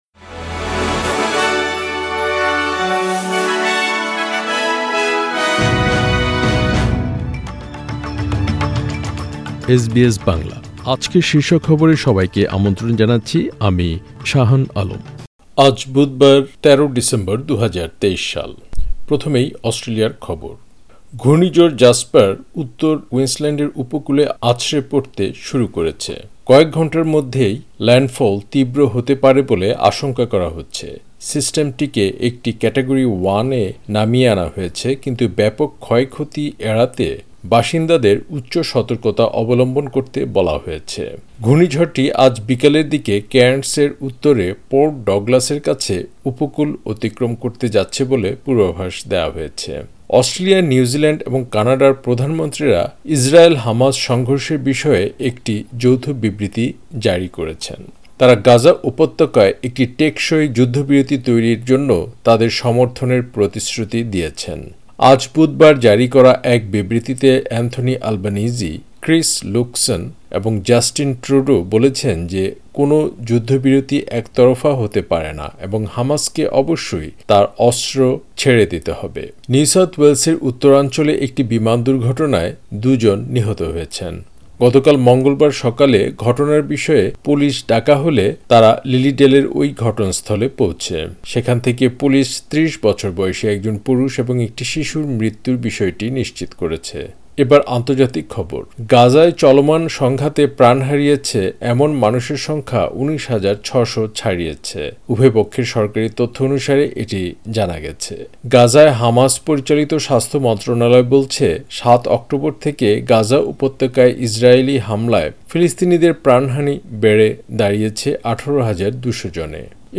এসবিএস বাংলা শীর্ষ খবর: ১৩ ডিসেম্বর, ২০২৩